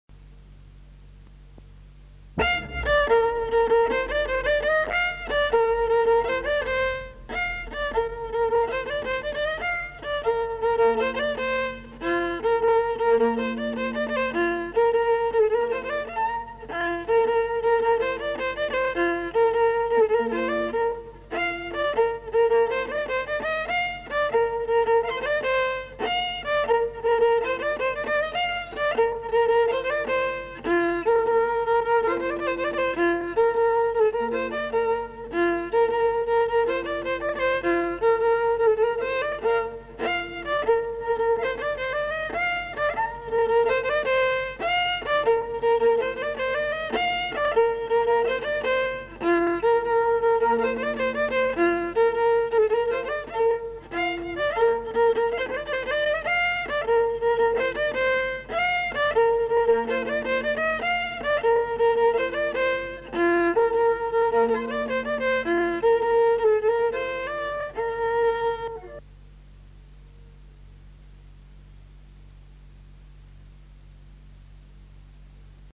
Aire culturelle : Haut-Agenais
Genre : morceau instrumental
Instrument de musique : violon
Danse : rondeau